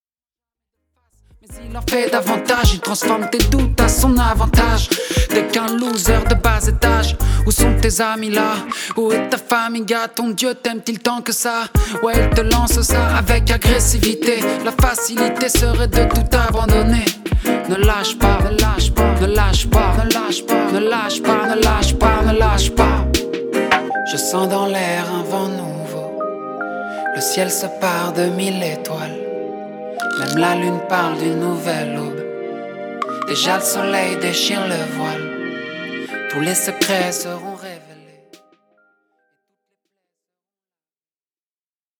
Le reggae reste néanmoins le fil conducteur de cet EP.